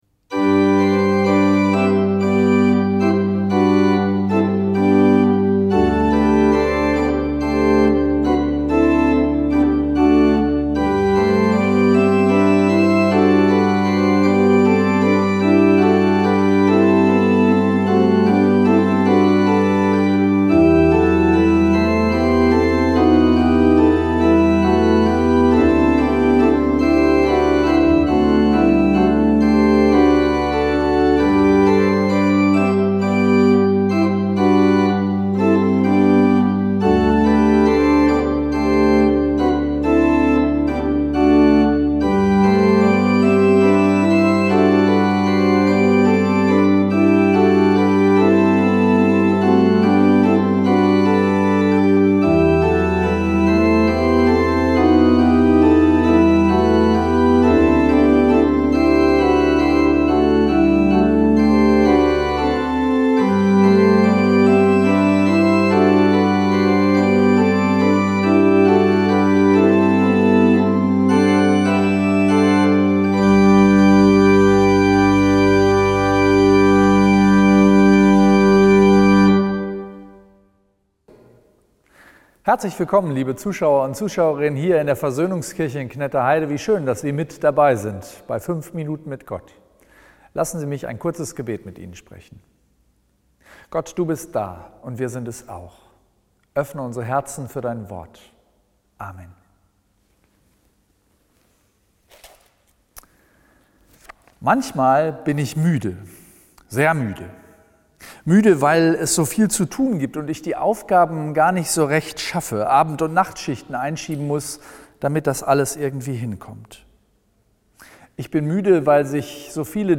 Musik für die Seele & Texte zum Nachdenken aus lutherischen Gemeinden und Kirchen der Lippischen Landeskirche